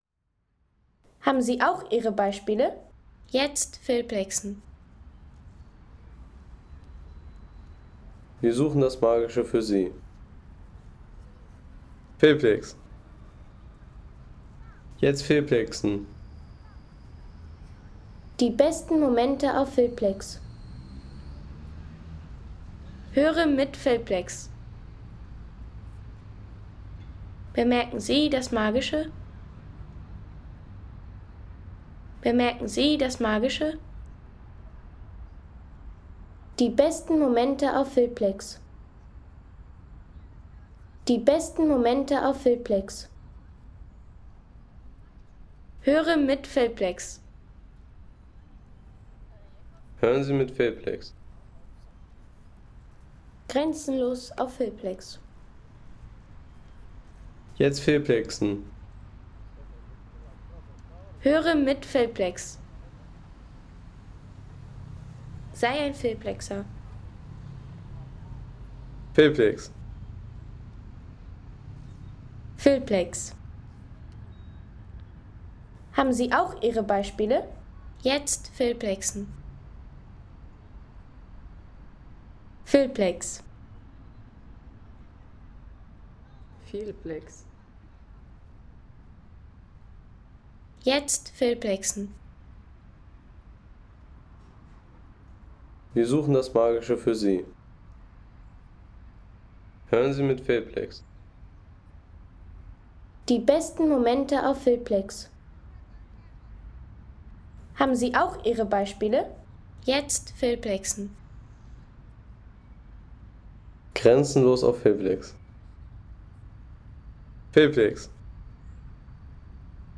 Stadtmoment Wien: Glockenspiel der Votivkirche